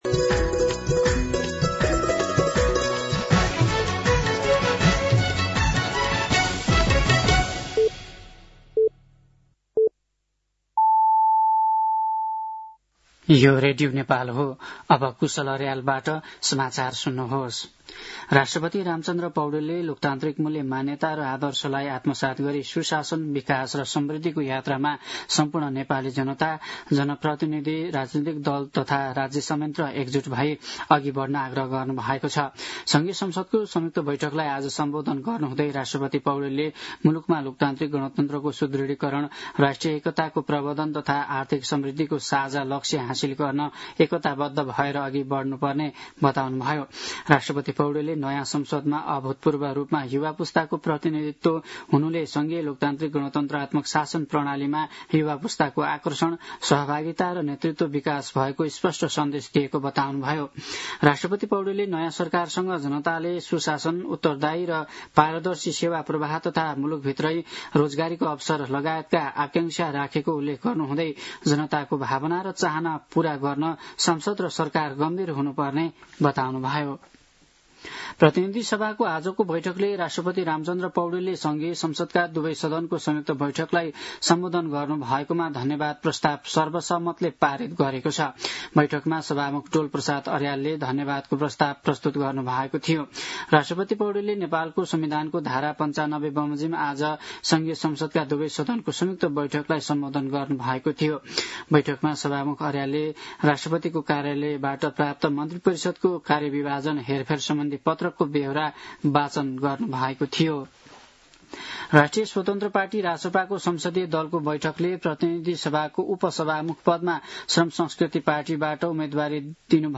साँझ ५ बजेको नेपाली समाचार : २७ चैत , २०८२